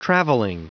Prononciation du mot travelling en anglais (fichier audio)
Prononciation du mot : travelling